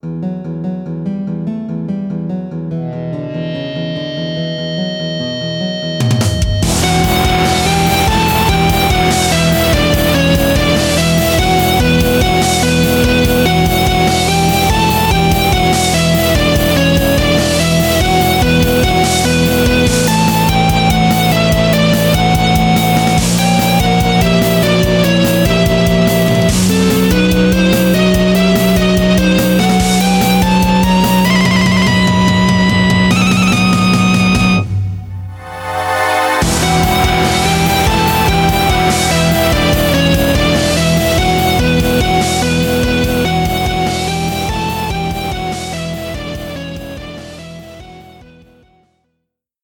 I wrote this handful of rock/metal songs as the soundtrack for a computer game my friends and I were creating.